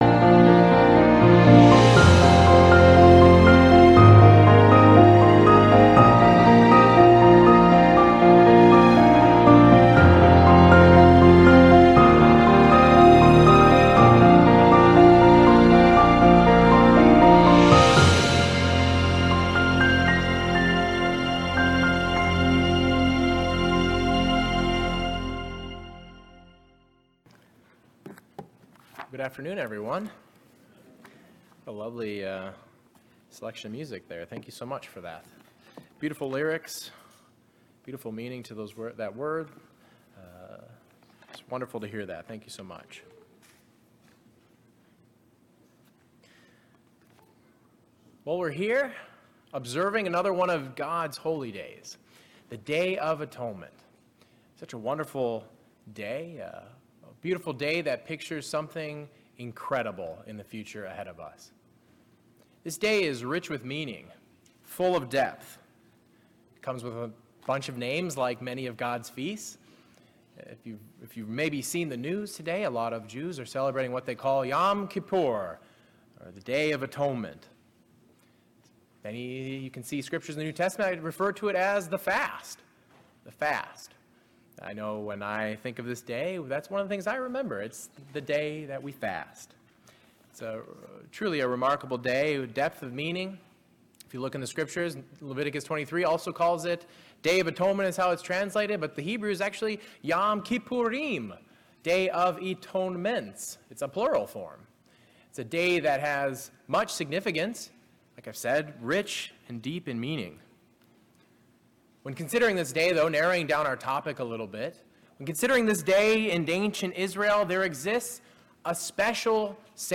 This sermon looks into these questions and reviews the true understanding revealed in God's word.